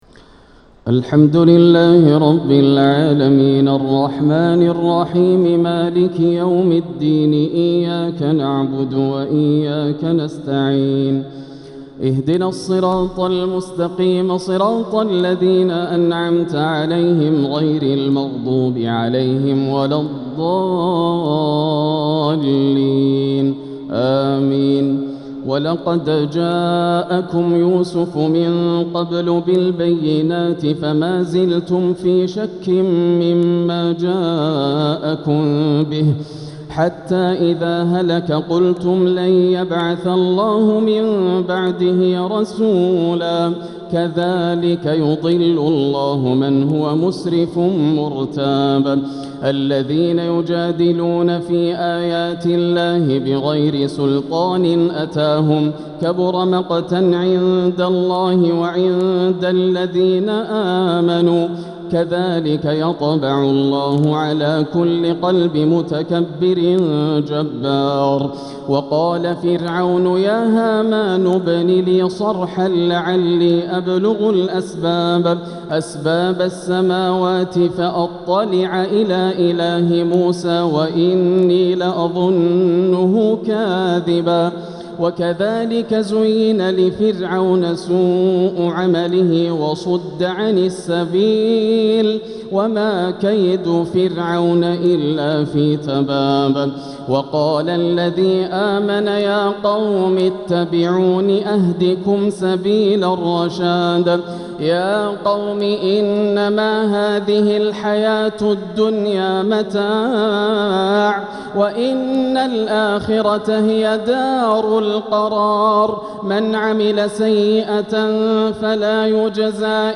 تهجد ليلة 25 رمضان 1446 من سورتي غافر (34-85) وفصلت (1-29) > الليالي الكاملة > رمضان 1446 هـ > التراويح - تلاوات ياسر الدوسري